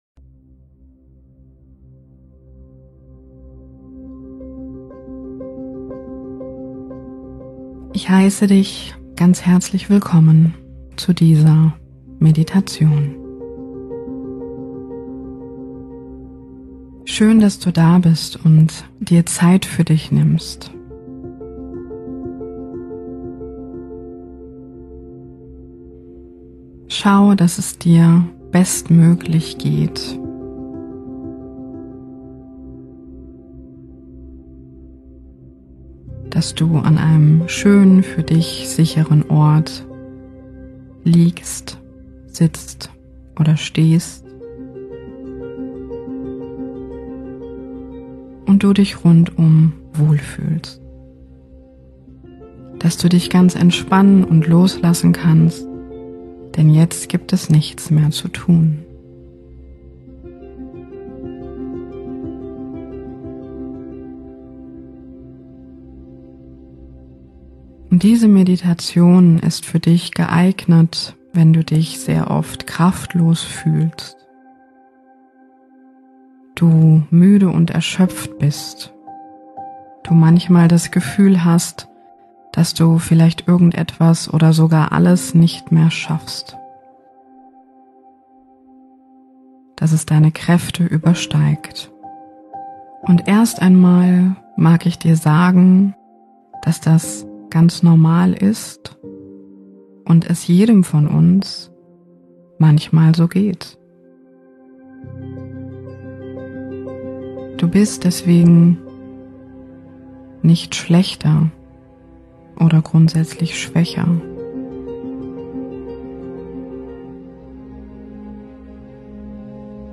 Meditation-Erwecke-deine-Staerke.mp3